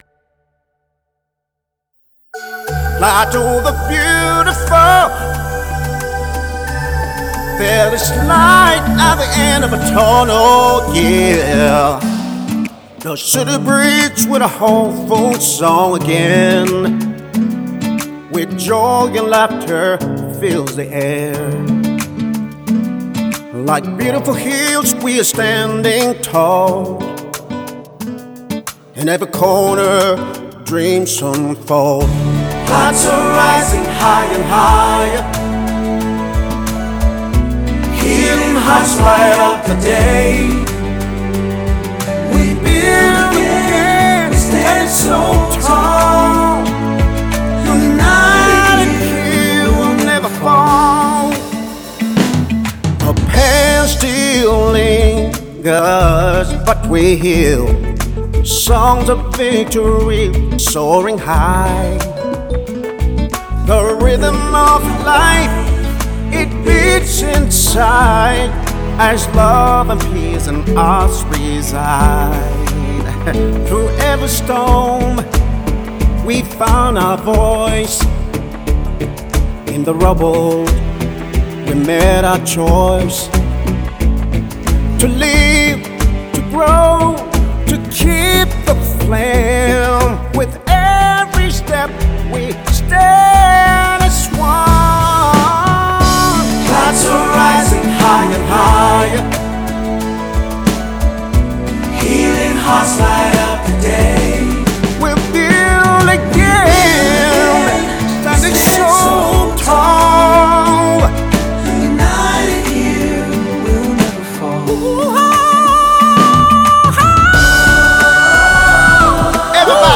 gospel and folk music
blends soulful melodies with traditional rhythms
passionate vocals and heartfelt lyrics